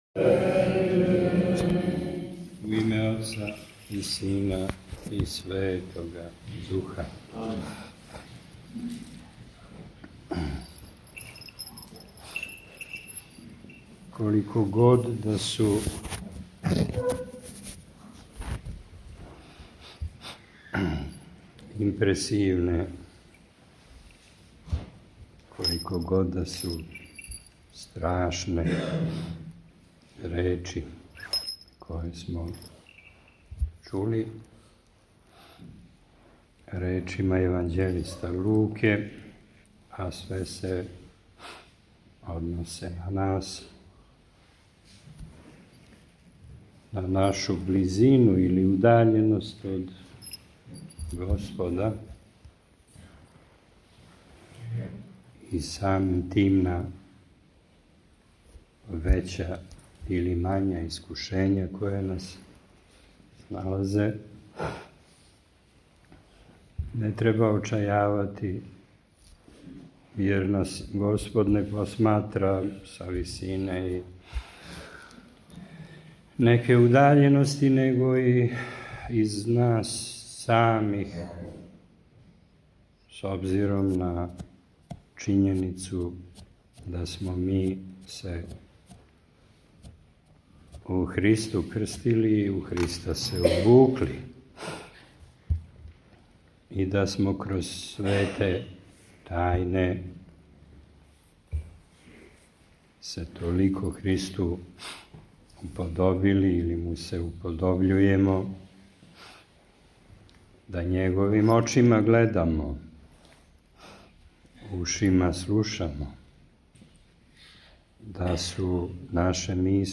Епископ Давид богослужио у крушевачком храму Васкрсења на Новом гробљу
Звучни запис беседе Беседећи после прочитаног Јеванђеља Епископ је поучио верни народ о смислу и значају помињања уснулих као и о посту коме се полако приближавамо.